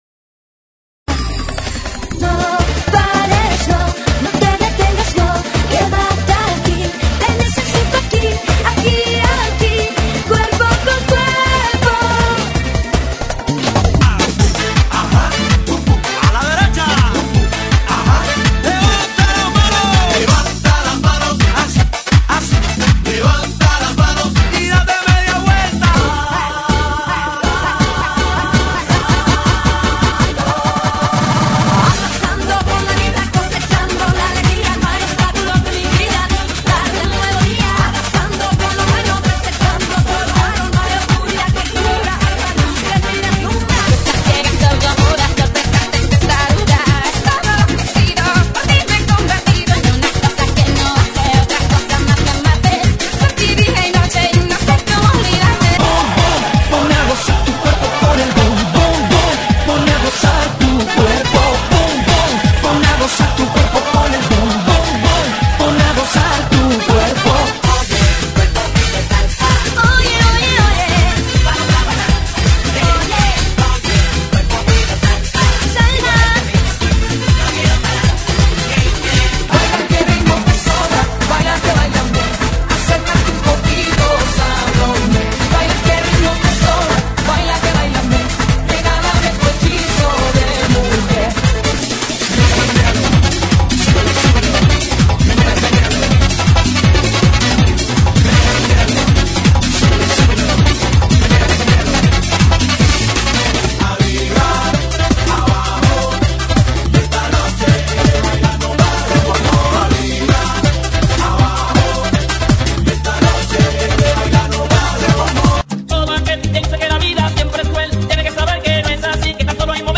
GENERO: LATINO – RADIO